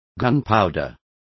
Complete with pronunciation of the translation of gunpowders.